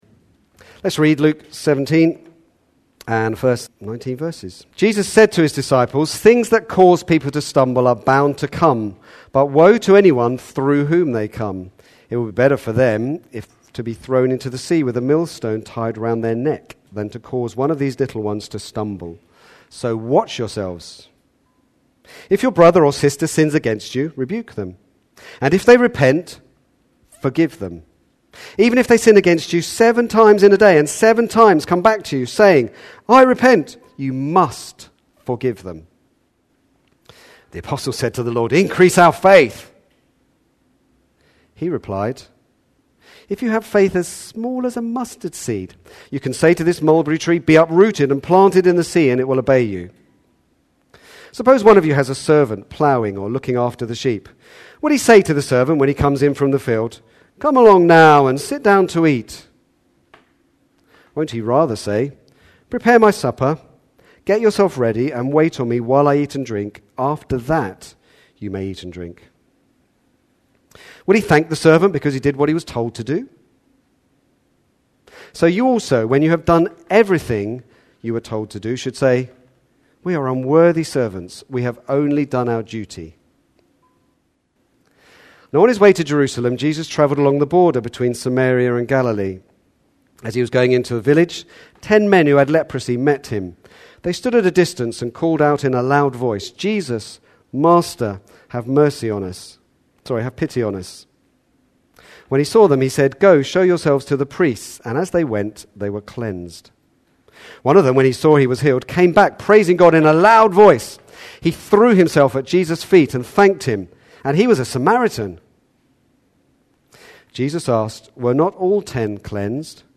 An independent evangelical church
Back to Sermons the duty and joy of forgiveness